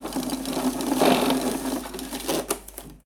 Tirar de un rollo de papel transparente
Cocina
Sonidos: Acciones humanas
Sonidos: Hogar